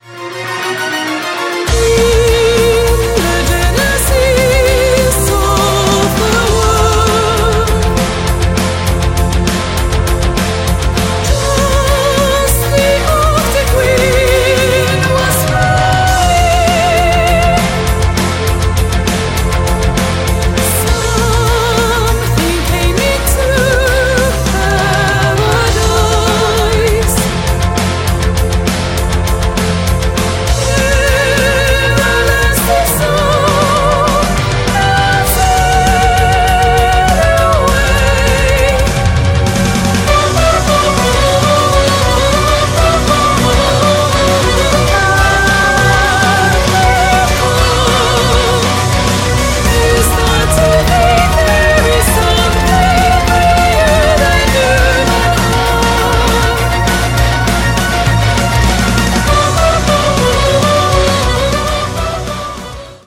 • Качество: 128, Stereo
Rock
Symphonic Metal